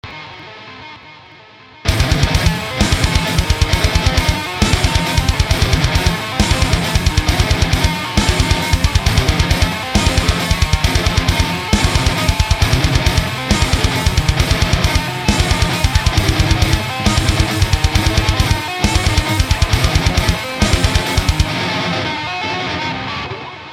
So I got this pretty cool tapping solo riff, but I can't get it to sit right in the mix.
I use direct recording due to my amp being at my bands practice place. I use ignite nrr-1 and impulses to get my tones, I get pretty good results until it comes to laying down my solos.
This is actually just one of my lead riffs over a simple rhythm track, I like layering my guitars like that, two different things going on at the same time.